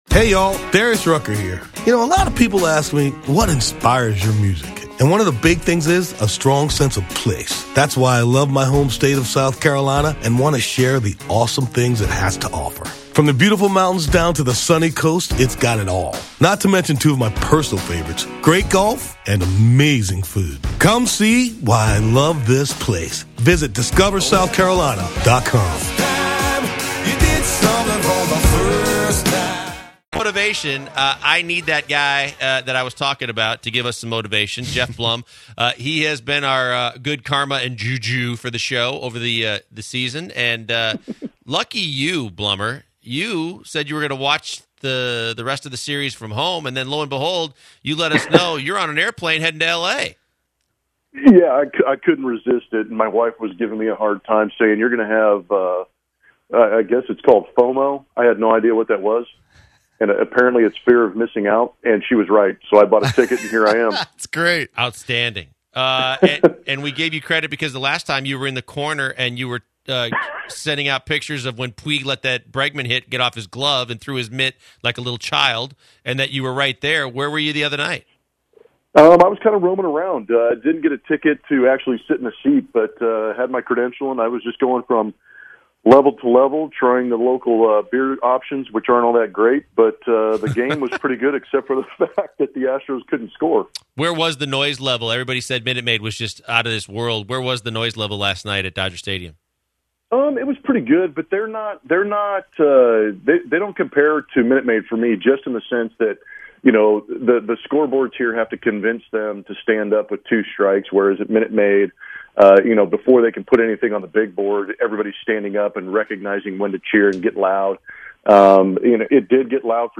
11.1.17 Geoff Blum Interview